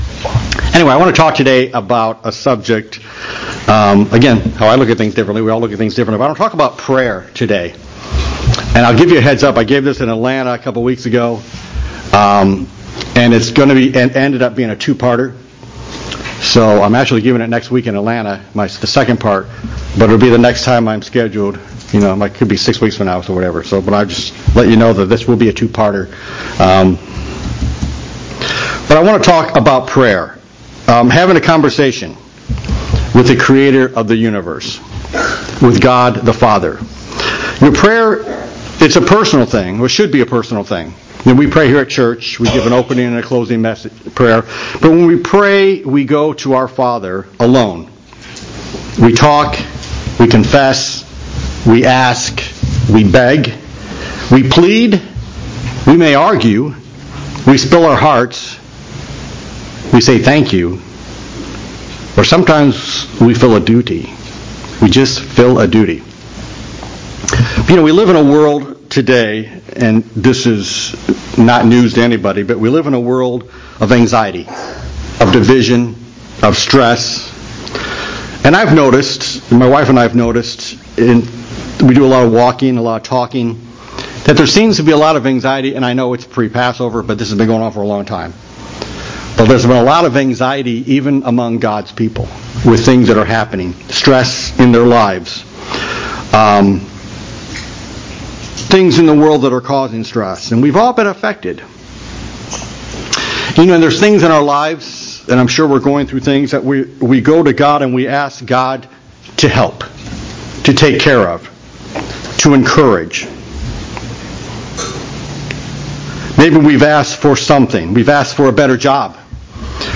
Prayer is an essential element between man and God. This sermon discusses some of those elements. But does God always hear us?
Given in Buford, GA